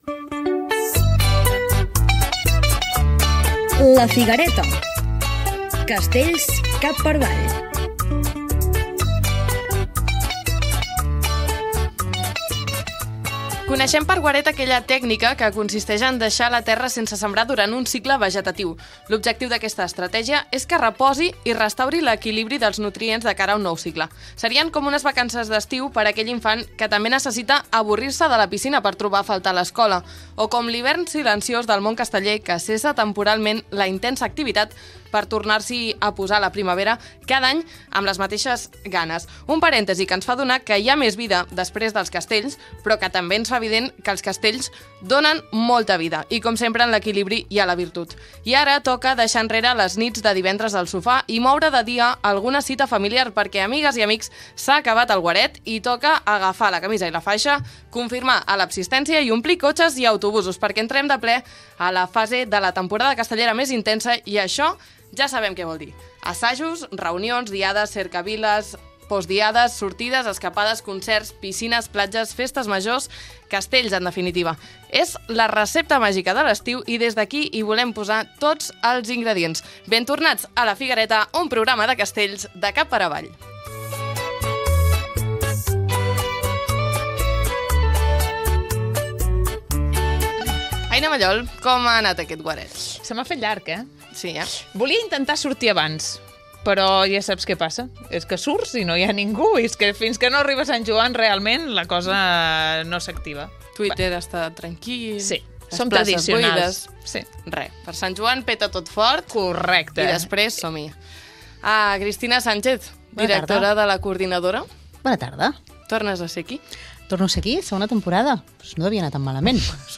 Pública municipal
Entreteniment